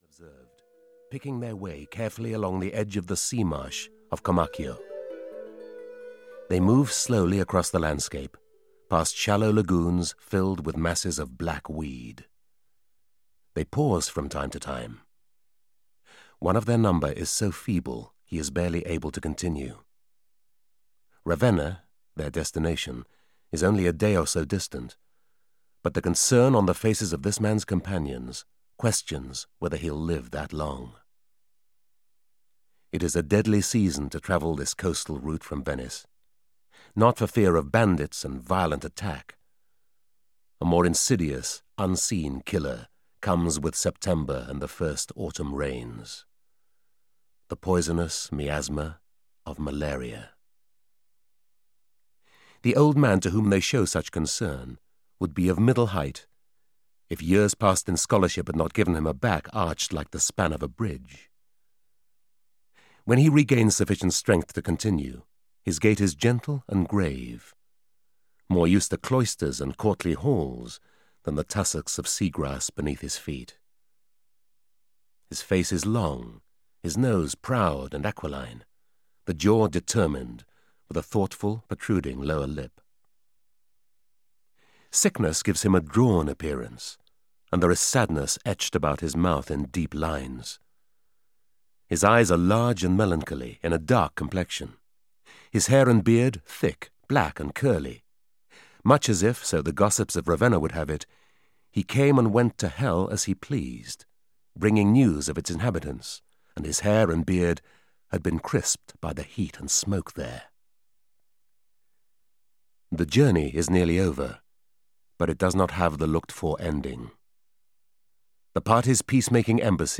A Life of Dante (EN) audiokniha
Ukázka z knihy
• InterpretJohn Shrapnel